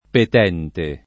petere [p$tere] v.; peto [p$to] — ant. latinismo per «chiedere» — part. pres. petente [